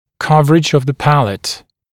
[‘kʌvərɪʤ əv ðə ‘pælət][‘кавэридж ов зэ ‘пэлэт]перекрытие неба, покрытие неба